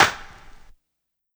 • Prominent Acoustic Snare Sample B Key 119.wav
Royality free acoustic snare tuned to the B note.
prominent-acoustic-snare-sample-b-key-119-3p7.wav